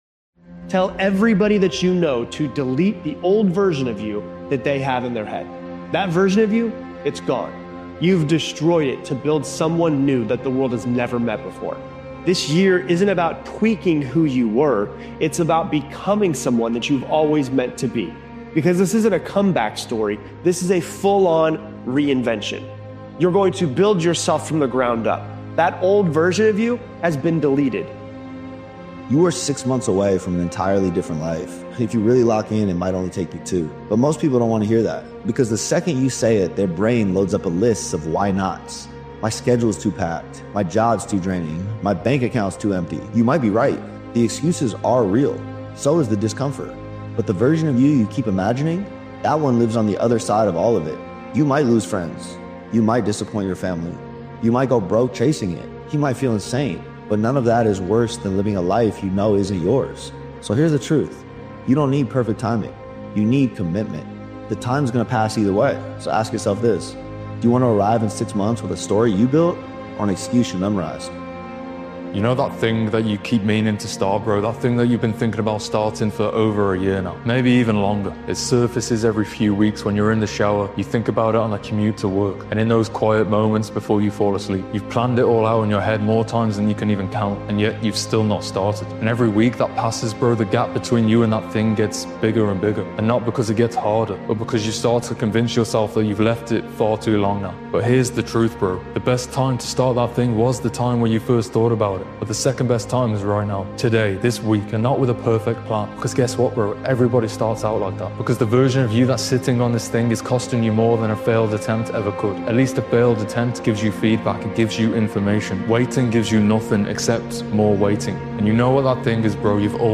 Powerful Motivational Speech Video. This powerful motivational speech episode by Daily Motivations is about raising your standards and finally putting yourself first.